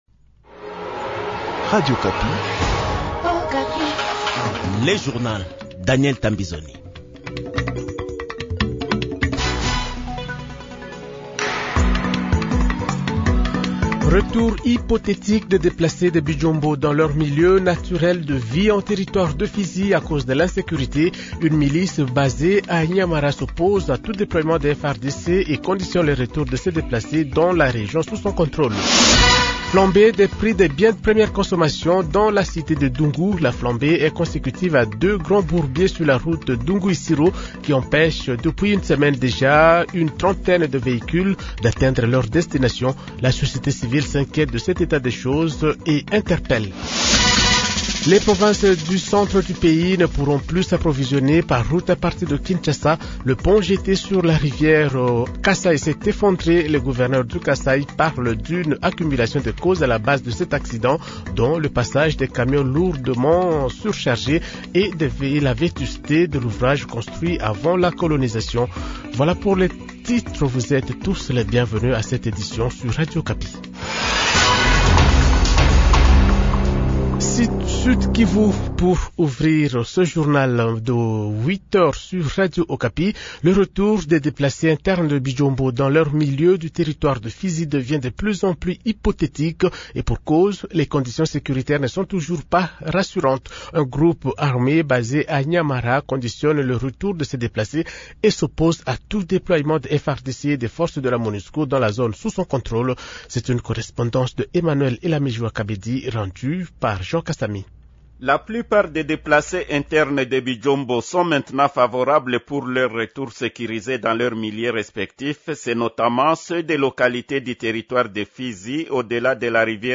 Journal Francais Matin 8h00